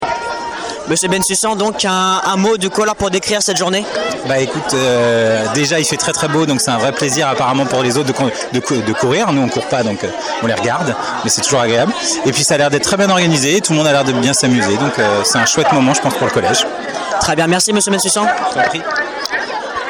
Interview
Le cross du collège 2025 !